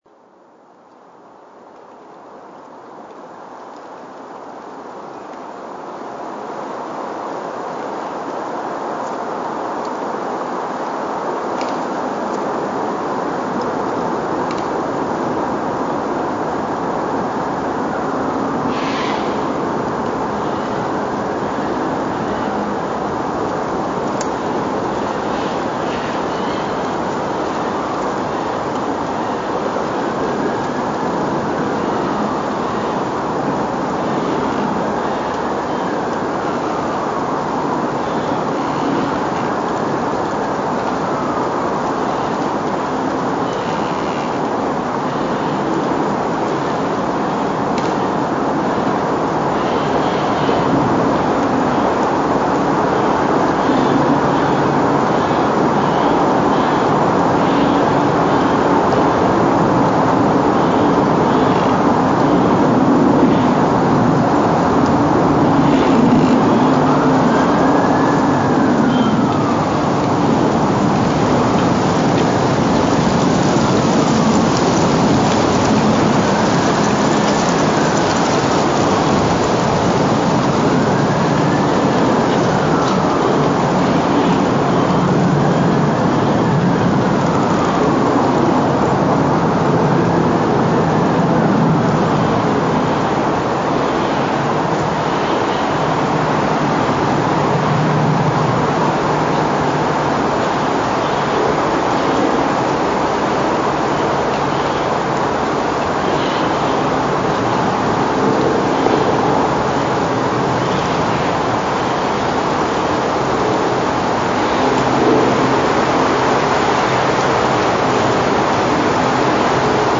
ki se zadnje čase pogosto identificira kot zvočni umetnik, ki snema naravo (t.i. poljski posnetki).